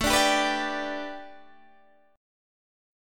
A7sus2sus4 chord